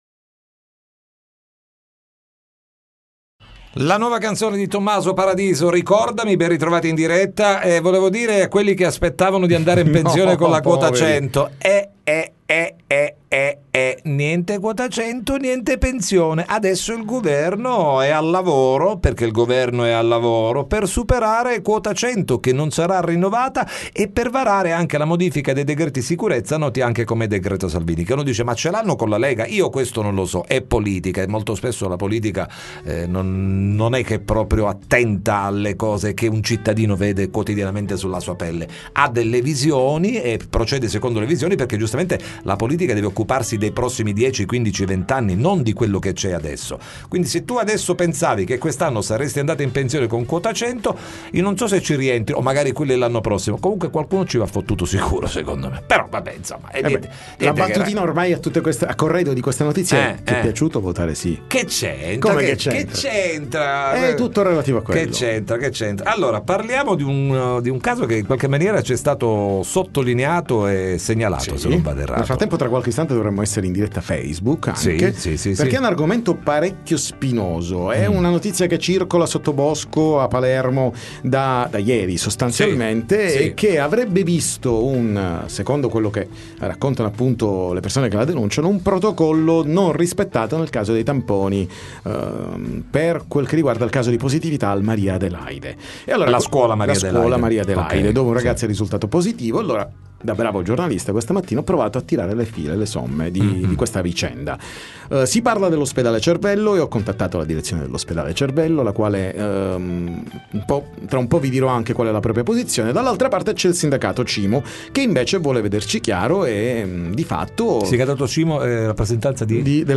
TM intervista